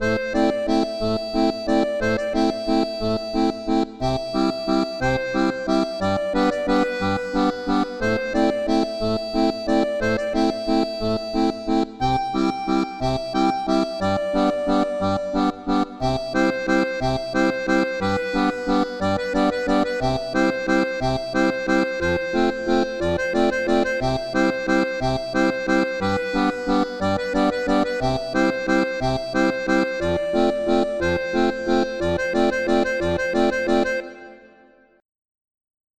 accordéon diatonique
Musique traditionnelle